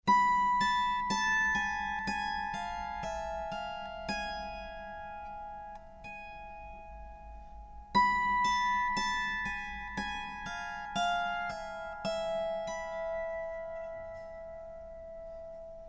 18 mélodies hébraïques à la cithare.
Musiques traditionnelles, adaptées pour cithare